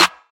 DDW4 SNARE 4.wav